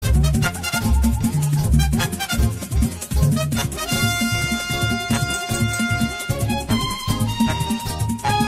PLAY DBZ Samba